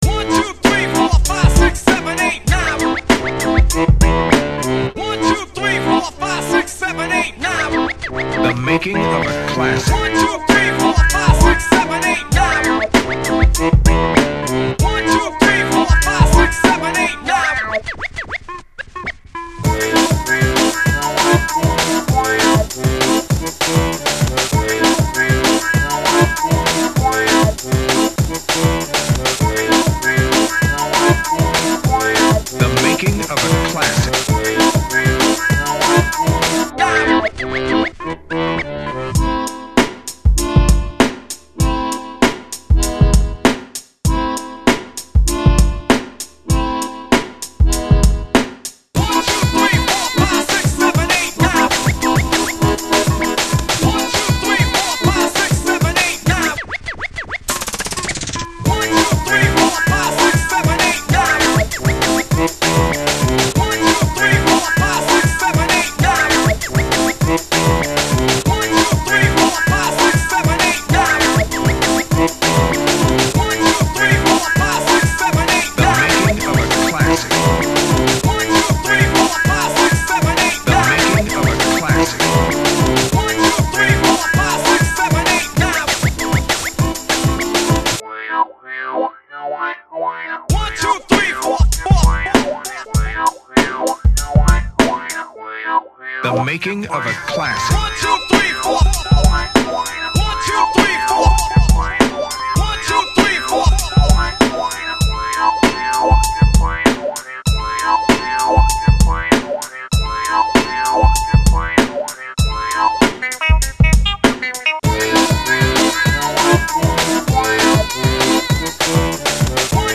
Брейк данс под баян break beat